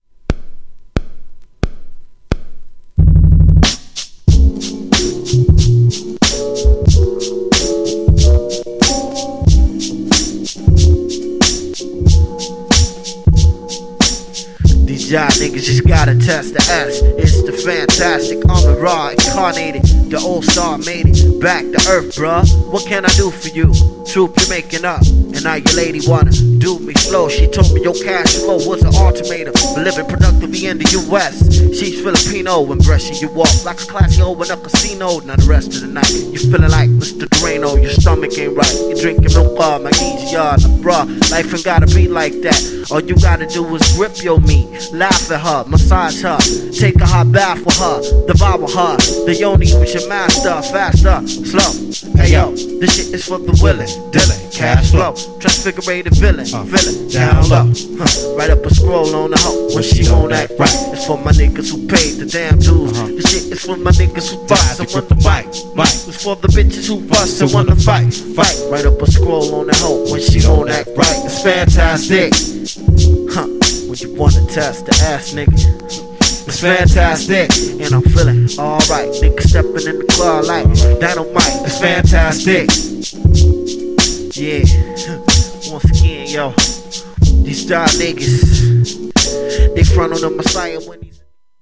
GENRE Hip Hop
BPM 91〜95BPM
# JAZZYなHIPHOP